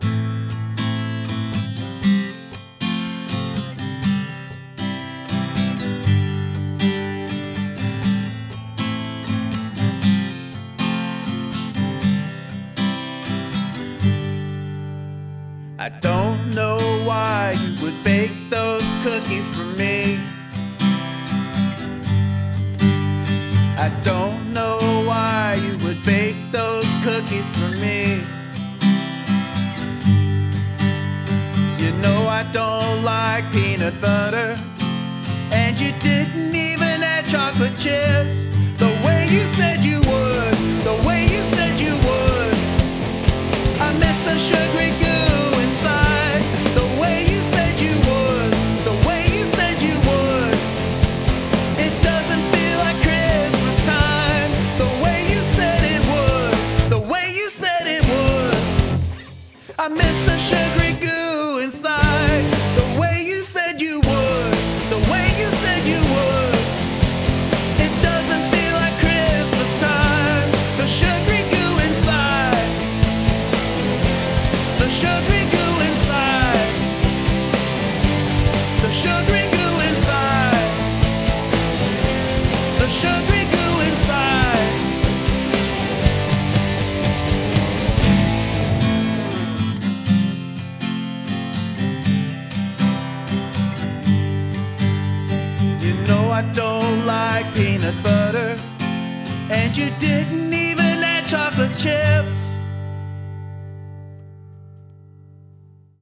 Nice groove and the chorus is excellent!